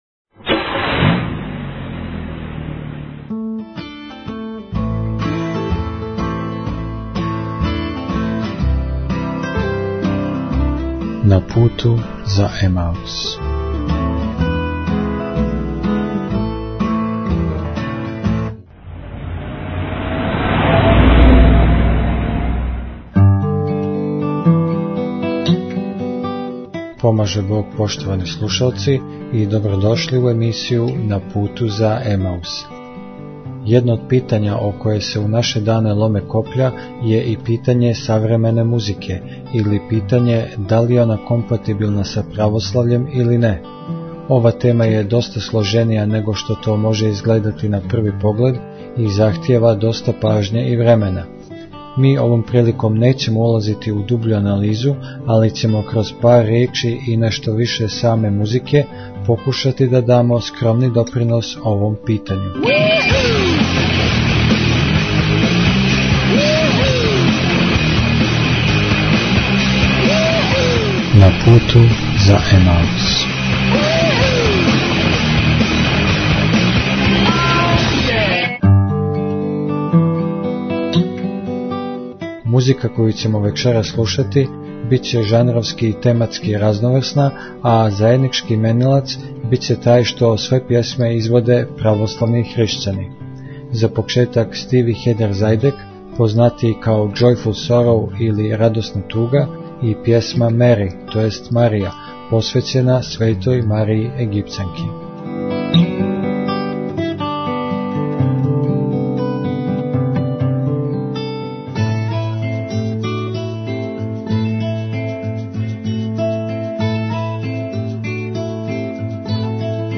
У вечерашњој емисији нећемо улазити у дубљу анализу овог питања, али ћемо кроз пар ријечи и нешто више музике покушати да дамо свој скромни допринос.